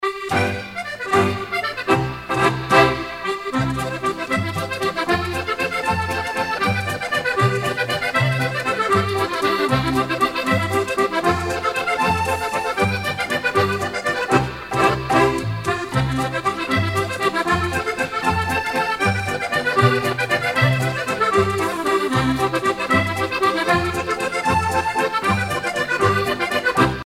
danse : valse musette
Pièce musicale éditée